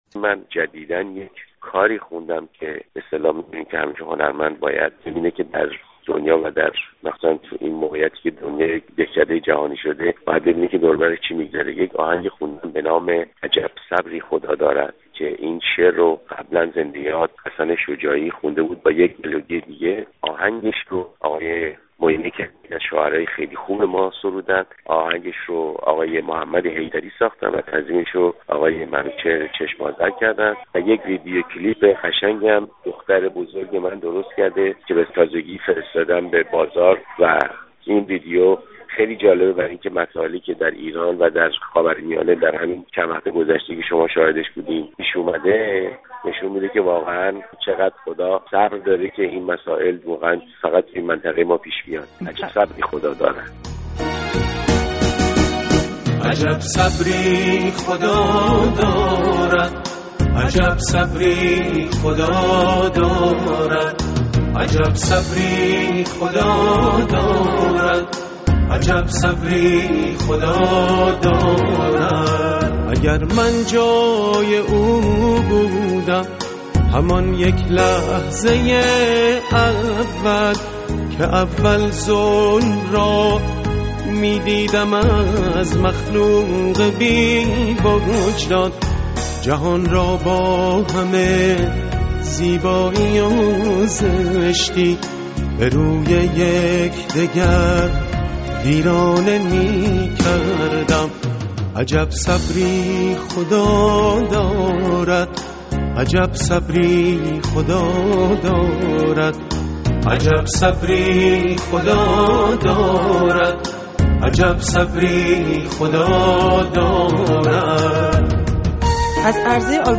گفت‌وگوی نوروزی با ستار